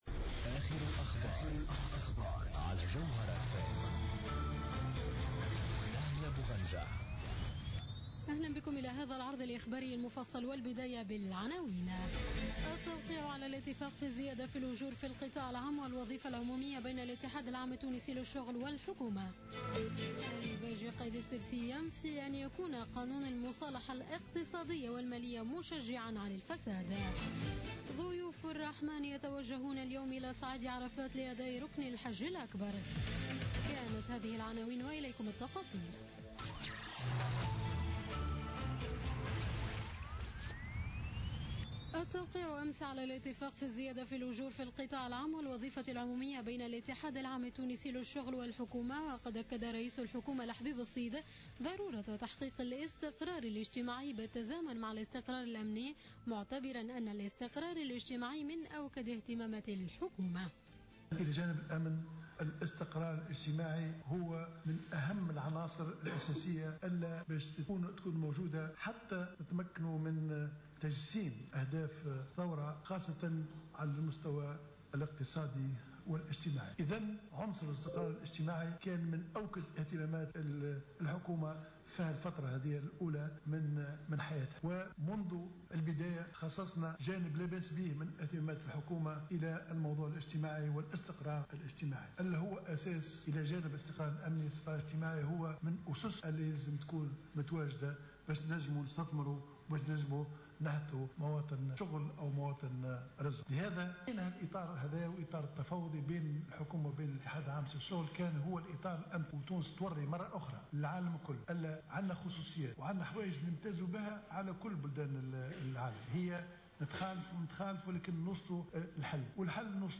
نشرة أخبار منتصف الليل ليوم الاربعاء 23 سبتمبر 2015